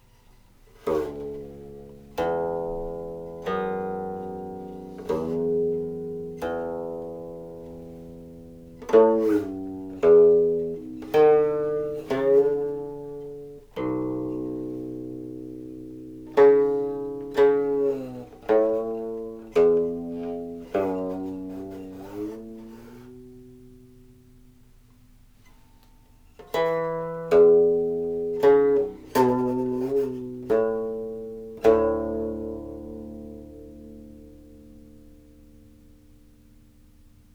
this qin setting for the lyrics in Section 8 of Gu Jiao Xing (Engaging with Old Friends).